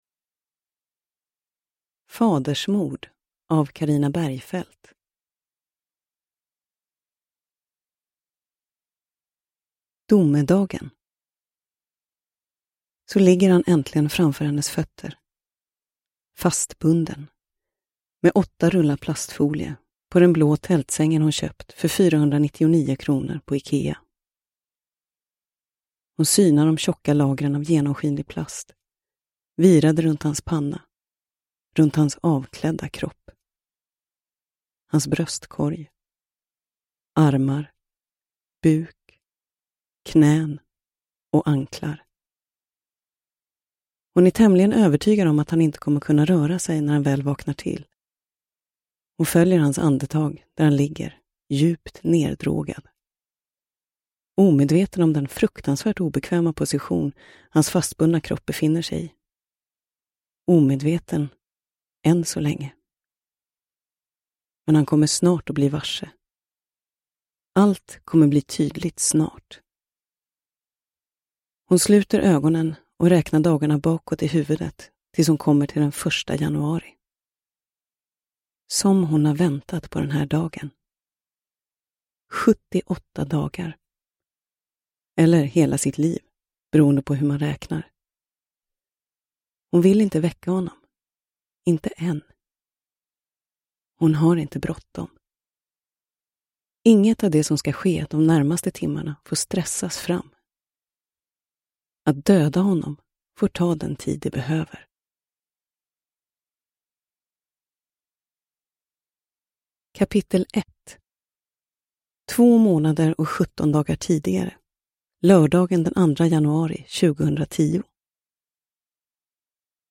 Fadersmord – Ljudbok – Laddas ner